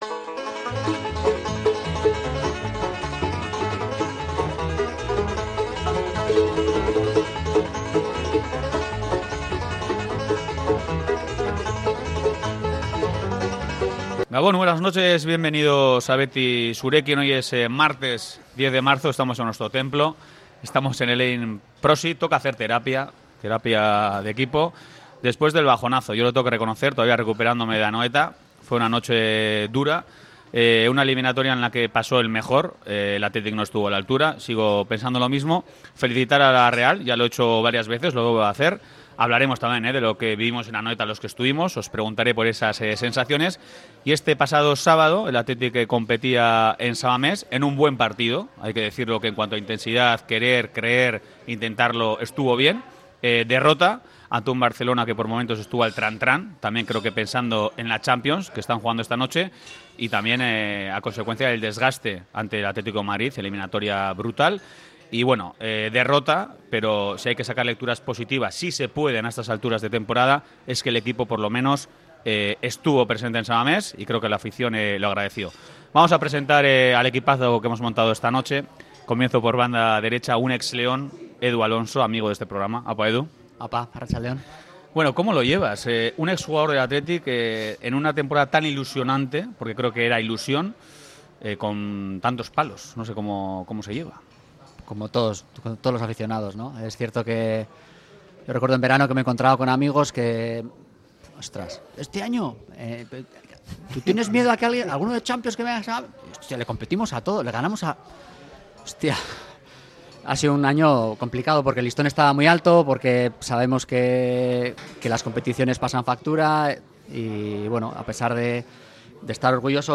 Mesa muy interesante y animada la de hoy